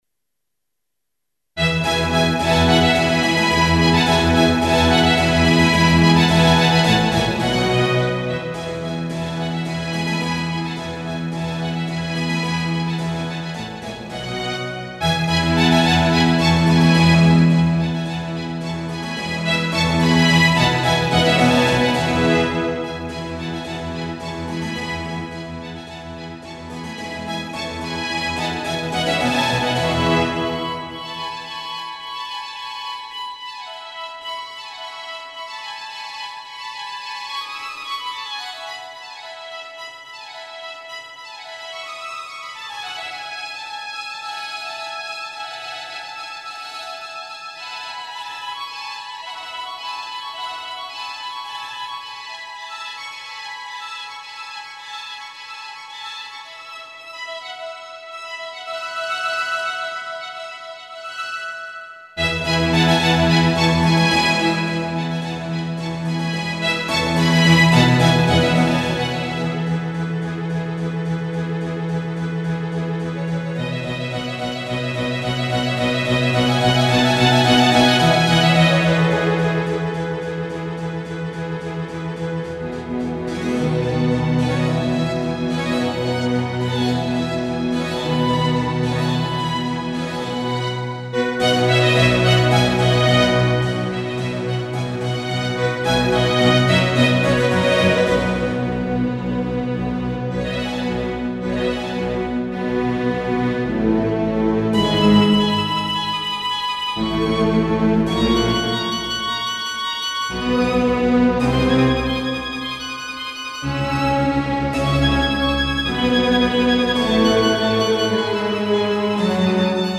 made with "Miroslav Philharmonik"
CLASSICAL MUSIC